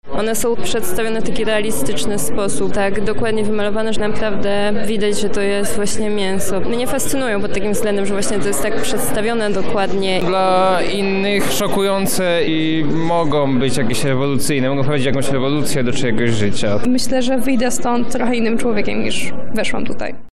Na wernisażu byli również nasi reporterzy.
Relacja z wernisażu wystawy „Meet and Meat”